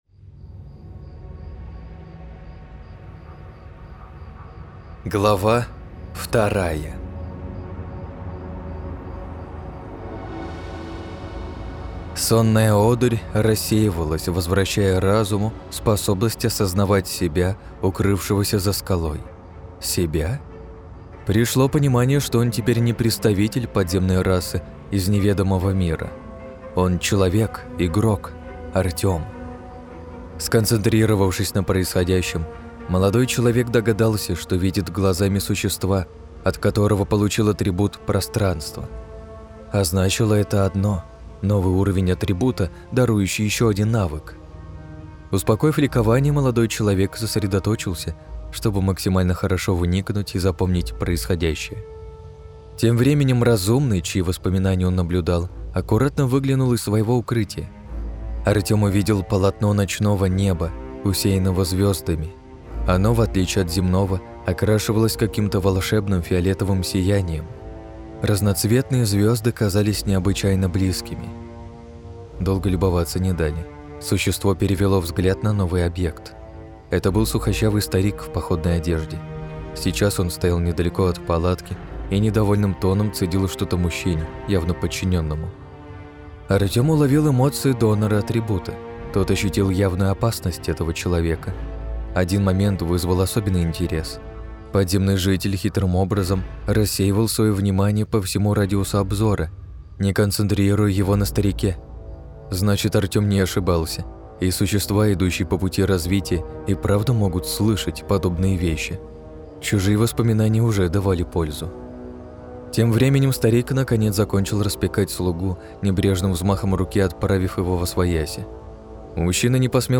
Аудиокнига - слушать онлайн